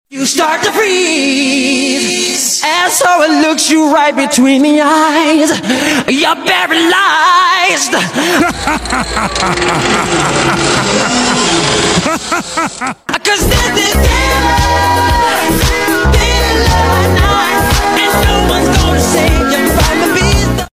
the laughter I added